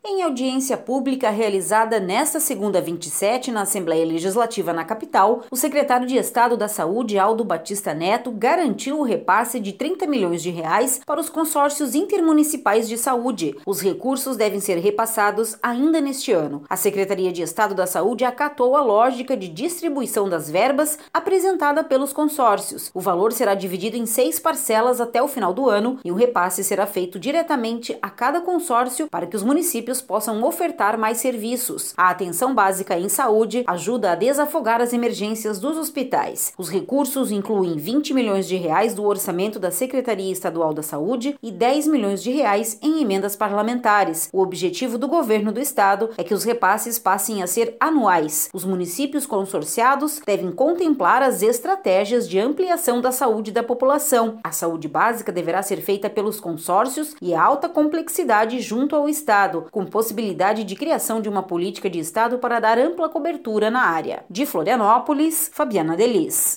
Em audiência pública realizada nesta segunda, 27, na Assembleia Legislativa, na Capital, o secretário de Estado da Saúde, Aldo Baptista Neto, garantiu o repasse de R$ 30 milhões para os Consórcios Intermunicipais de Saúde.